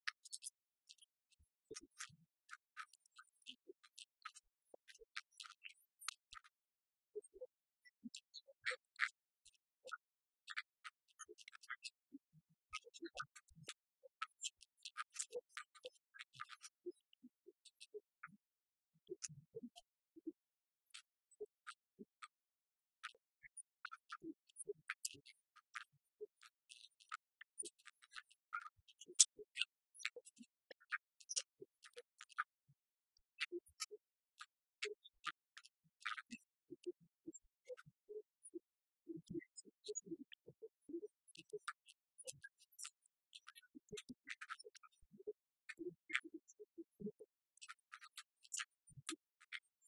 Al nostro microfono: